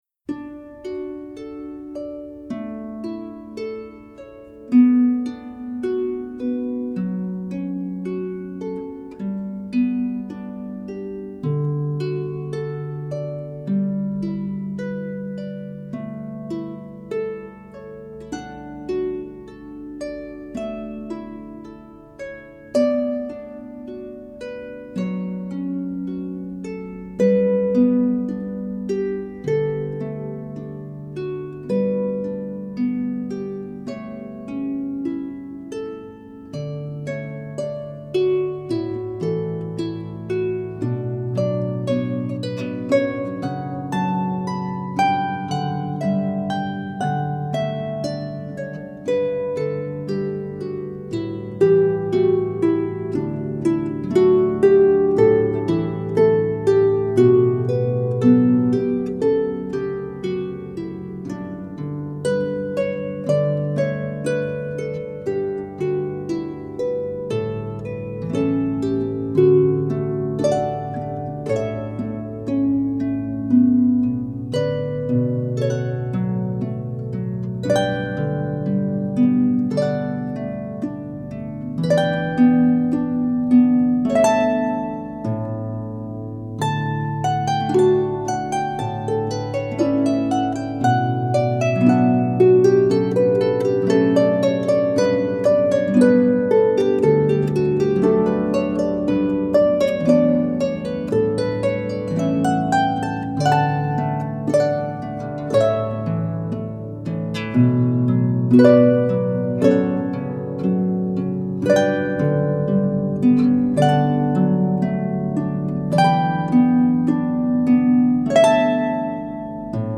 for solo lever or pedal harp